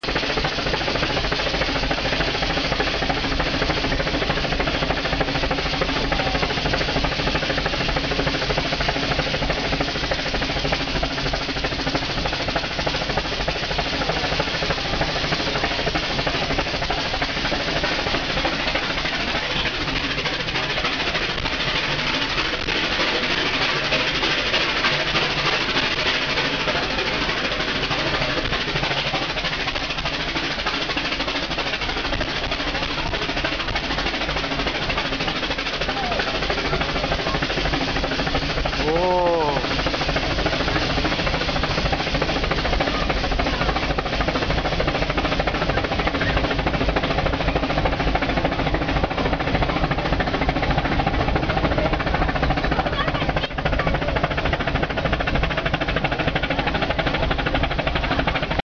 アプト式急勾配をドラフト音を響かせてゆっくりゆっくり登る。
ＳＬの音（音量に注意）
この【ＳＬの音】は針葉樹林から岩の切通しを抜け草原が広がるまでの約１分です。